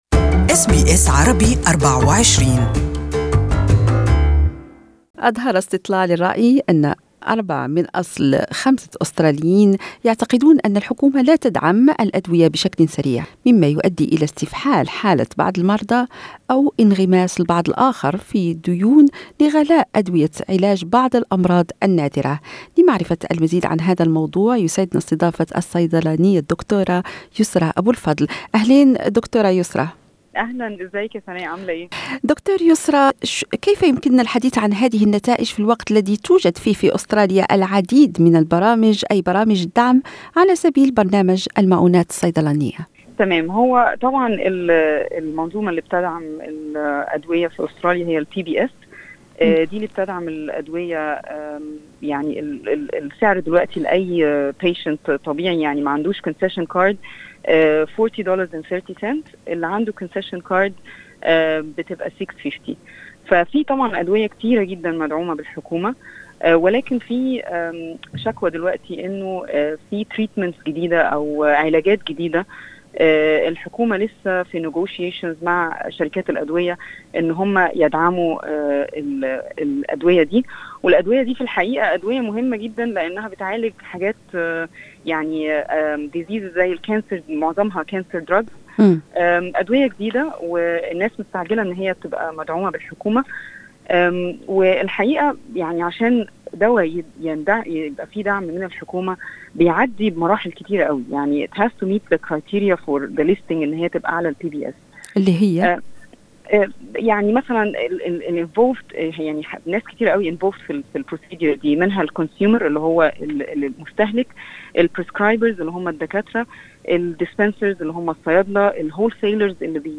تحدثنا الى الصيدلانية